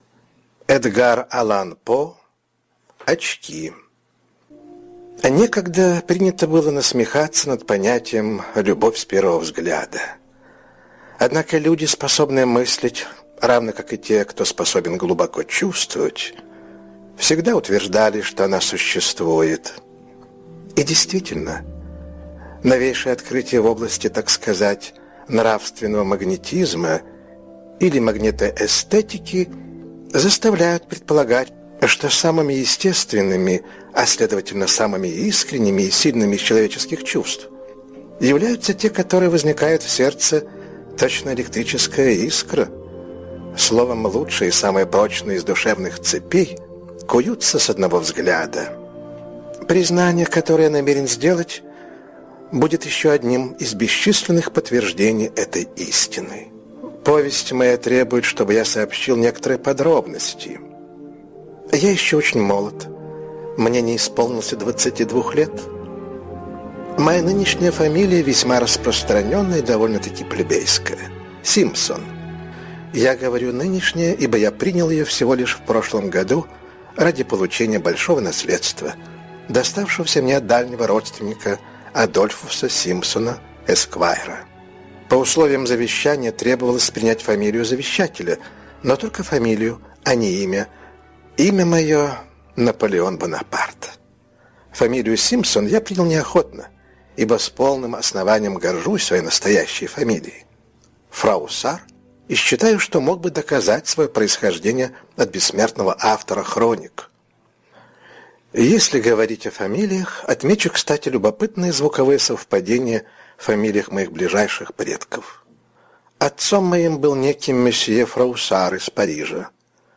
Очки - аудио рассказ Эдгара По - слушать онлайн